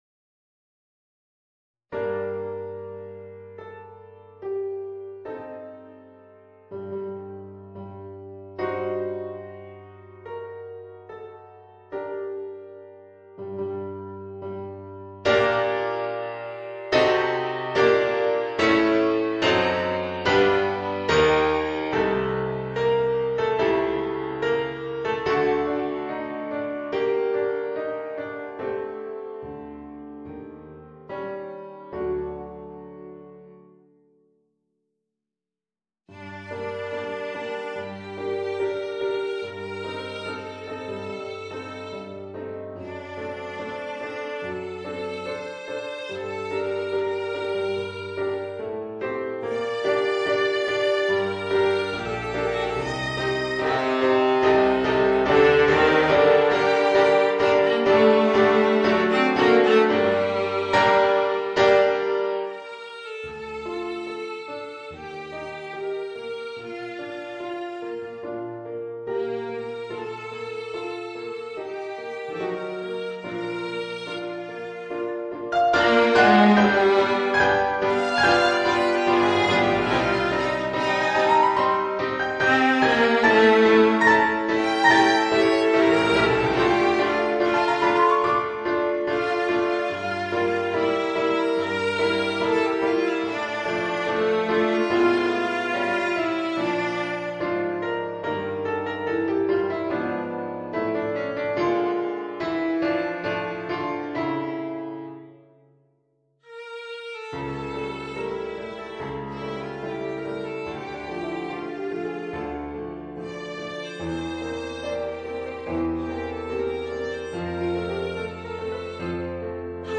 Bratsche & Klavier